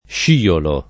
sciolo [ + š & olo ] s. m.